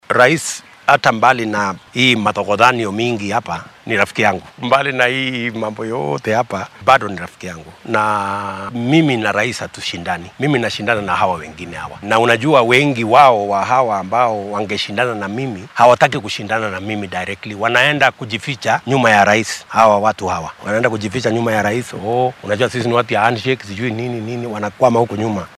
Wareysi gaar ah oo uu siiyay telefishinka Citizen ayuu ku sheegay in madaxweynaha dalka Uhuru Kenyatta uunan ka mid ahayn musharraxiinta sanadka 2022-ka balse ay jiraan shakhsiyaad doonaya inay taageero ka helaan.